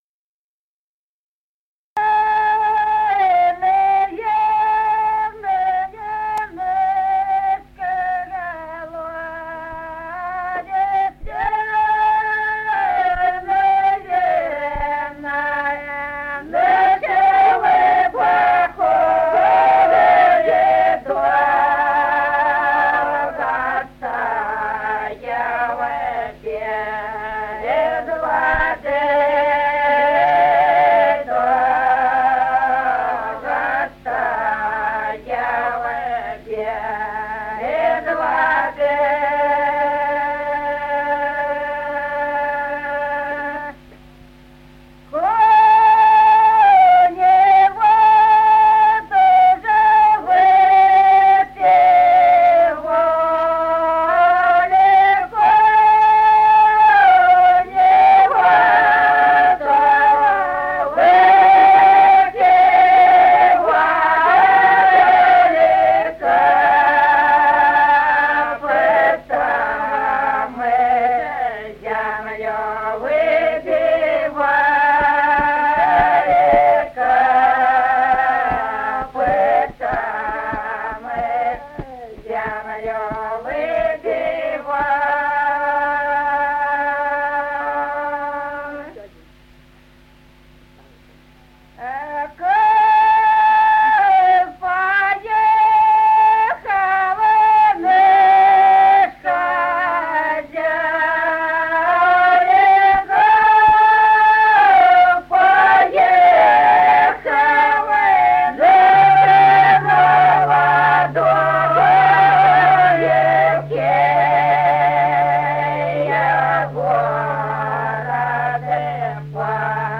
Народные песни Стародубского района «Верный наш колодезь», карагодная.
с. Остроглядово.